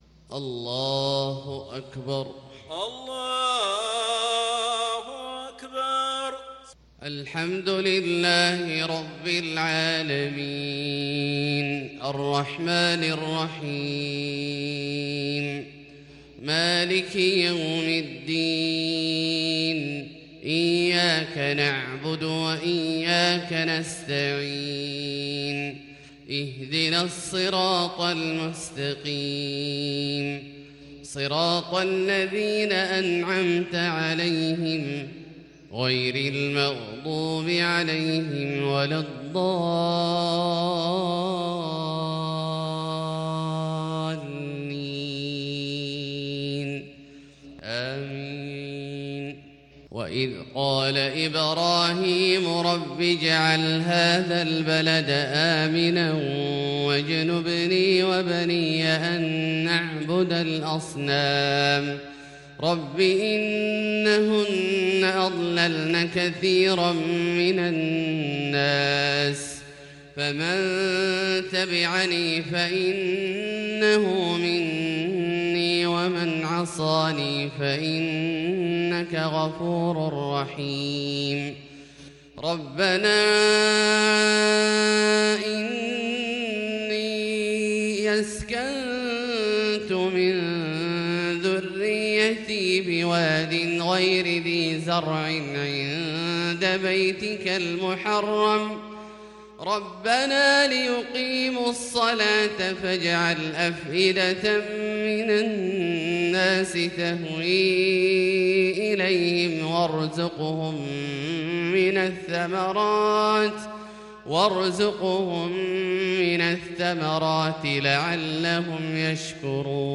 صلاة الفجر للقارئ عبدالله الجهني 9 ربيع الآخر 1442 هـ
تِلَاوَات الْحَرَمَيْن .